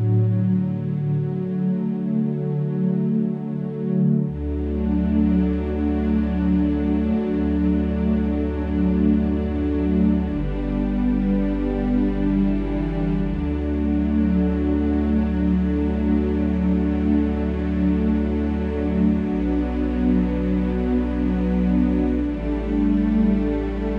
No Backing Vocals Easy Listening 3:35 Buy £1.50